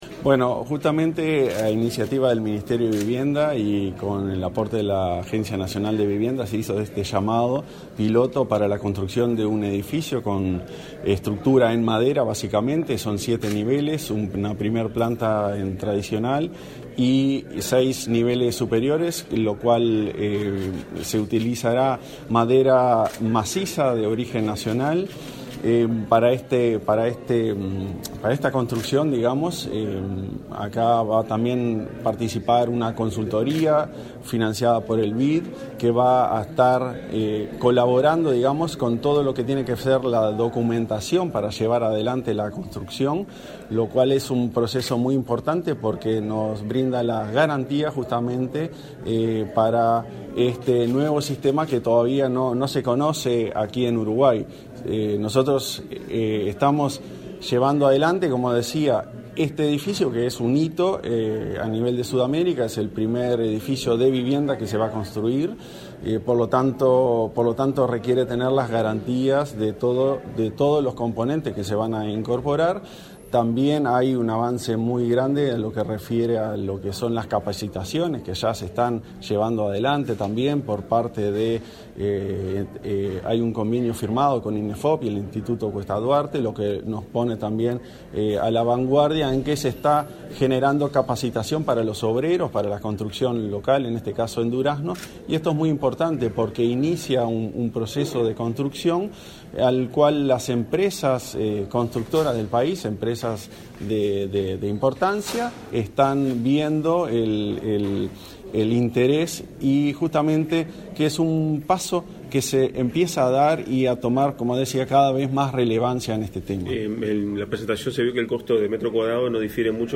Declaraciones a la prensa del presidente de la ANV, Klaus Mill
Declaraciones a la prensa del presidente de la ANV, Klaus Mill 30/08/2023 Compartir Facebook X Copiar enlace WhatsApp LinkedIn Tras participar en la presentación del proyecto piloto del primer edificio de madera en Uruguay, este 30 de agosto, el presidente de la Agencia Nacional de Vivienda (ANV), Klaus Mill, realizó declaraciones a la prensa.